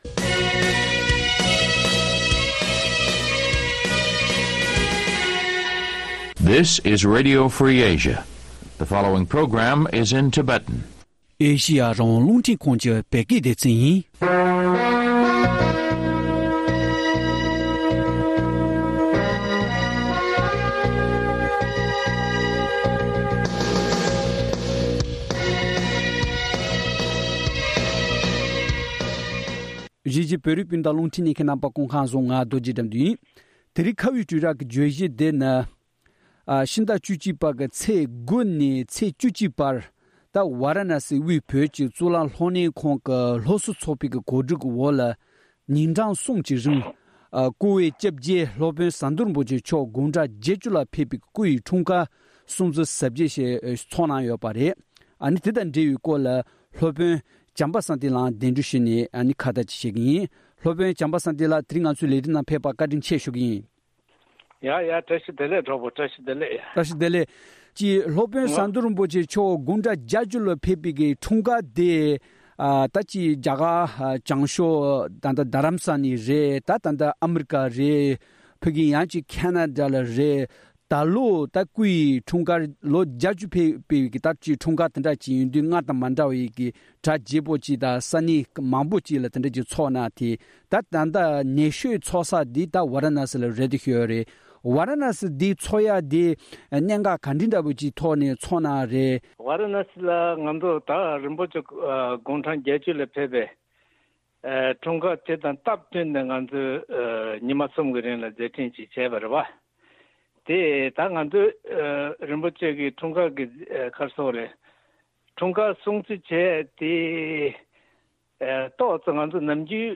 བཅའ་འདྲི་བྱེད་པ་ཞིག་ཉན་རོགས་ཞུ།།